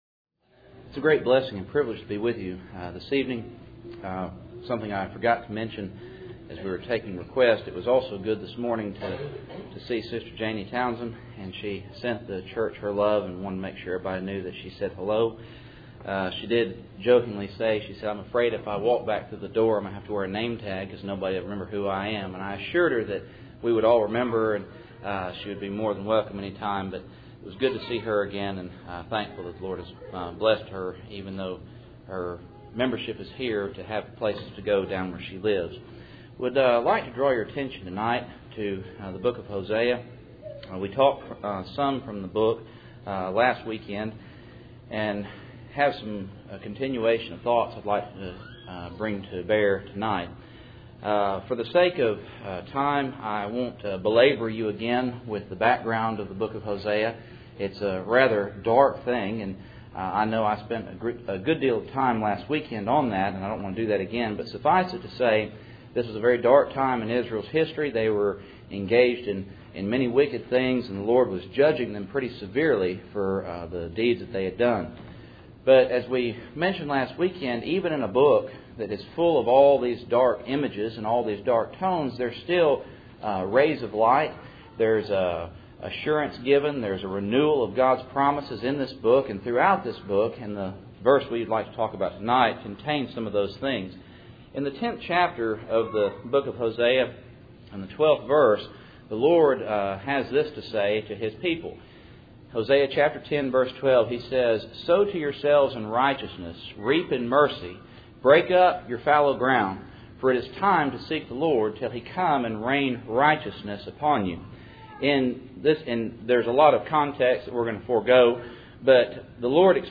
Hosea 10:12 Service Type: Cool Springs PBC Sunday Evening %todo_render% « I Peter 2:7-10